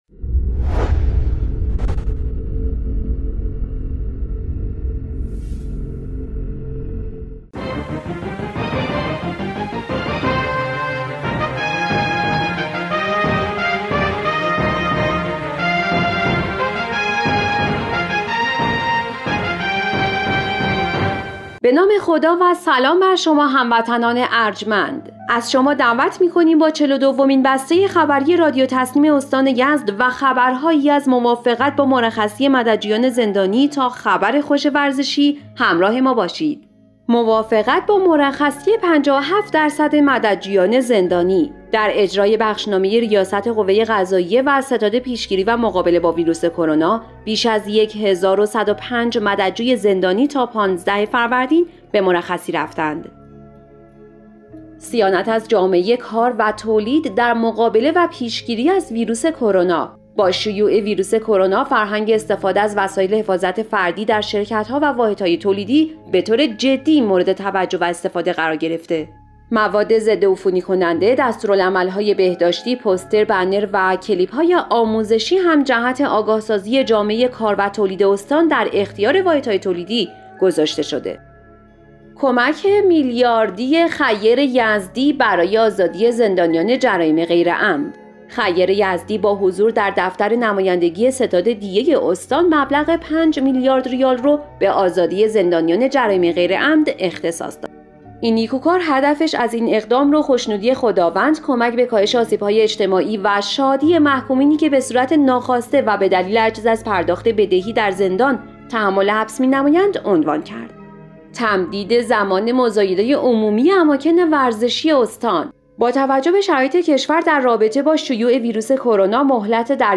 به گزارش خبرگزاری تسنیم از یزد, چهل و دومین بسته خبری رادیو تسنیم استان یزد به خبرهایی چون مرخصی 75 درصد مددجویان زندانی, صیانت از جامعه کار در مقابله با ویروس کرونا, کمک خیر یزدی به آزادی جرائم غیرعمد, تمدید زمان مزایده عمومی اماکن ورزشی استان و صعود شمشیرباز یزدی به جدول اصلی جام جهانی لوکزامبورگ اختصاص دارد.